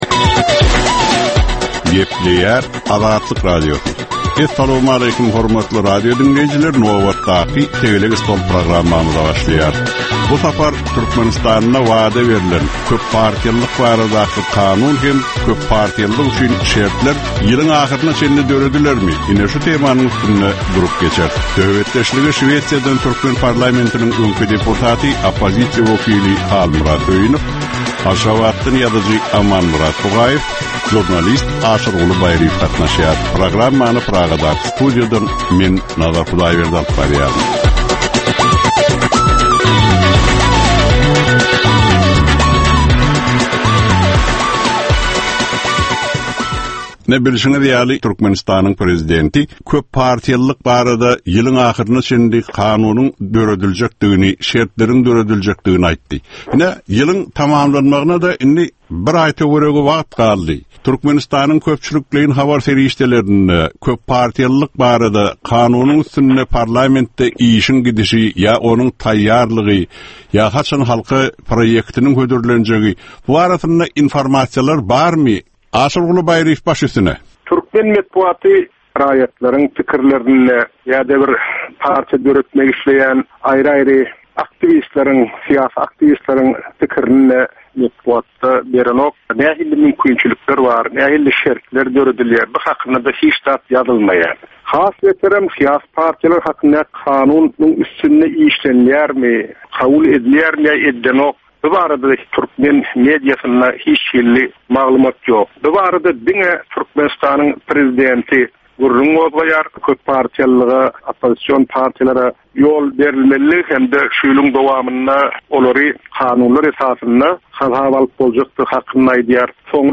Jemgyýetçilik durmuşynda bolan ýa-da bolup duran soňky möhum wakalara ýa-da problemalara bagyşlanylyp taýýarlanylýan ýörite “Tegelek stol” diskussiýasy. Bu gepleşikde syýasatçylar, analitikler we synçylar anyk meseleler boýunça öz garaýyşlaryny we tekliplerini orta atýarlar.